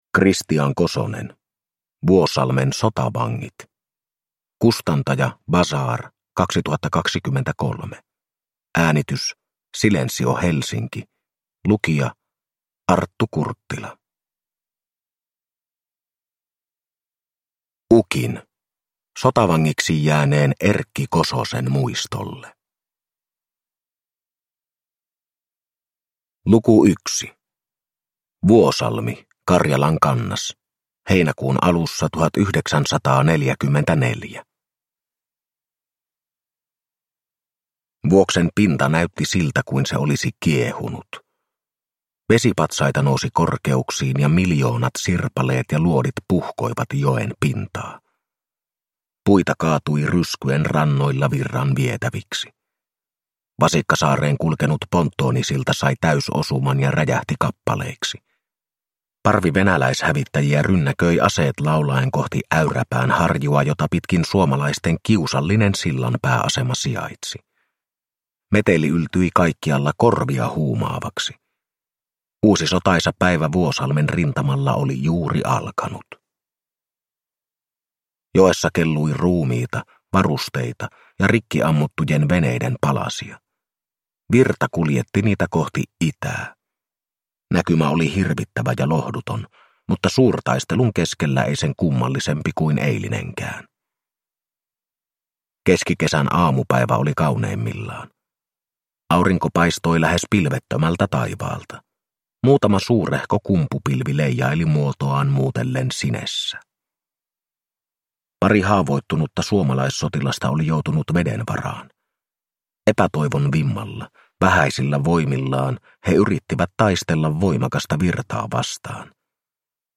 Vuosalmen sotavangit – Ljudbok – Laddas ner